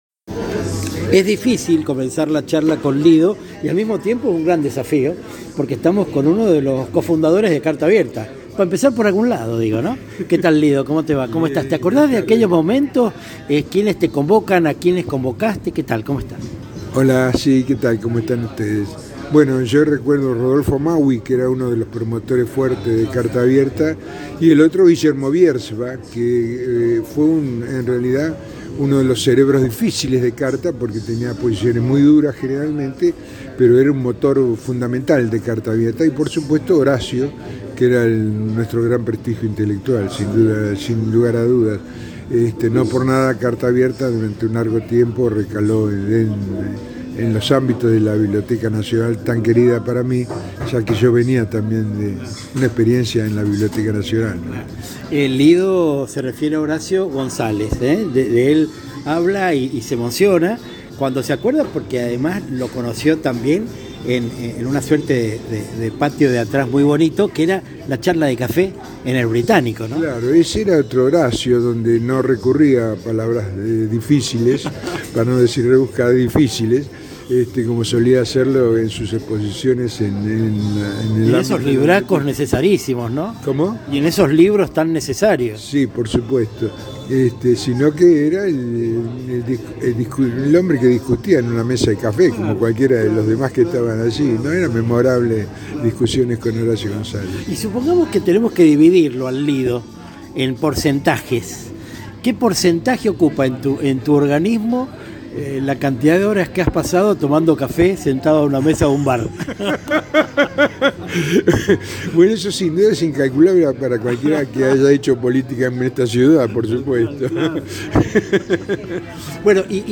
De allí el porqué de un anecdotario estimable, que abarca aquellas de orden personal y muchas de tipo político, en el sentido de las gestiones que lo tuvieron como uno de los dirigentes que tomaba decisiones. Vas a escucharlo emocionarse al recordar todo lo hecho desde la Comisión Nacional de Bibliotecas Populares. A tris de un clic y líneas abajo, espero que disfrutes de nuestro encuentro, al bode de una de las mesas del bar La Poesía, del histórico barrio de la populosa Buenos Aires.